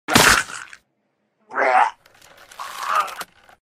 Hello soyteens, I need an -ACK sound effect o algo